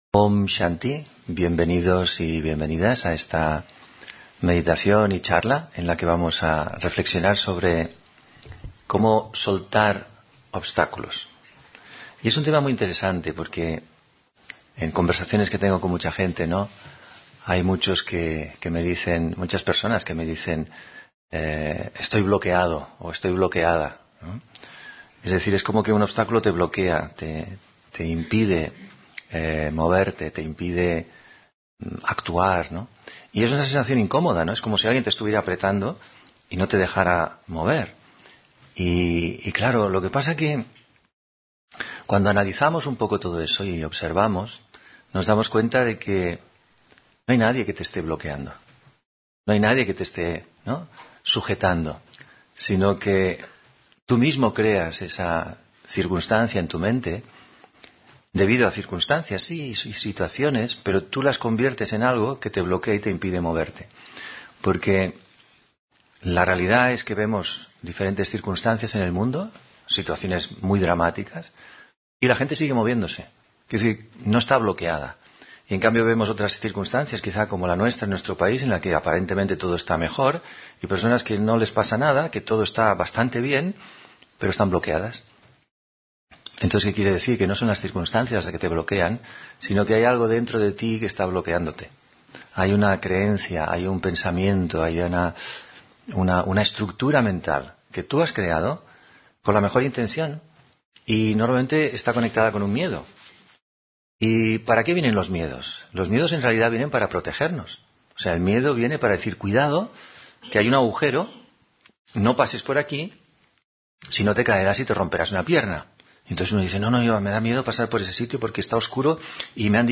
Meditación y conferencia: La felicidad de una mente serena (6 Marzo 2026)